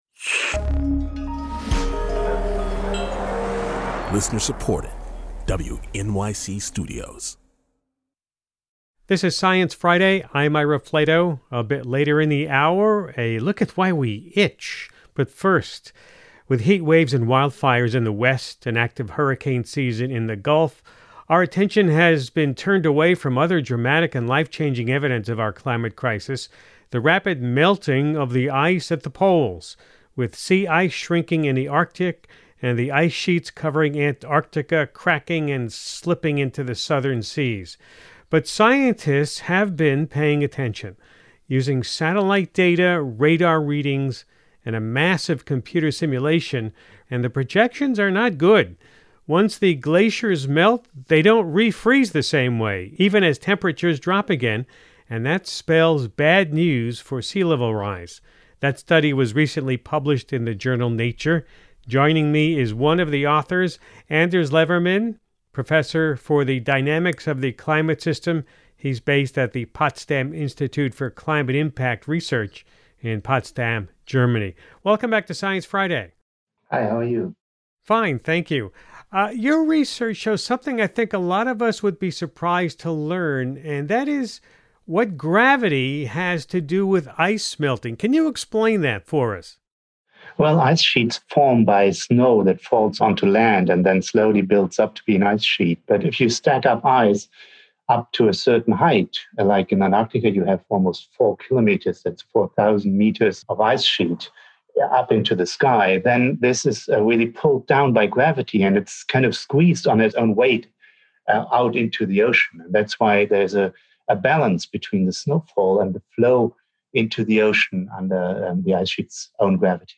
They were joined by a live Zoom audience, who were also itching to ask their own questions.